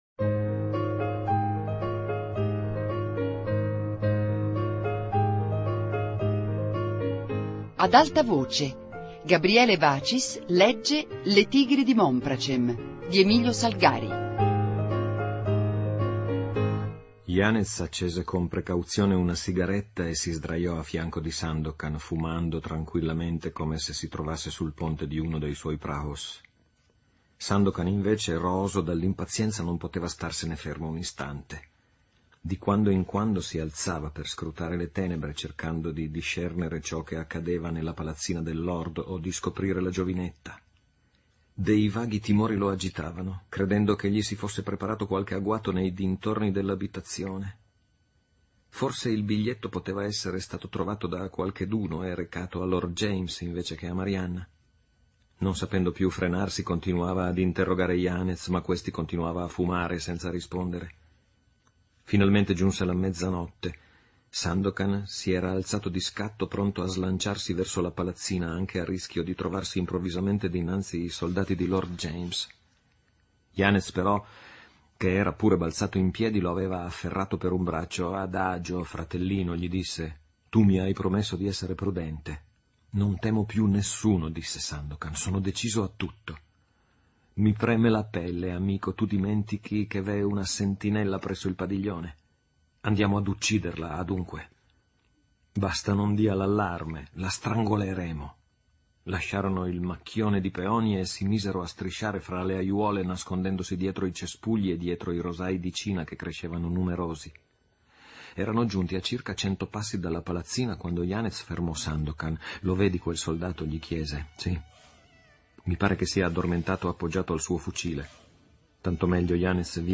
Le Tigri di Mompracem - Lettura VIII